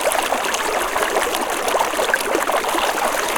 water sounds nice.
fountain-2.ogg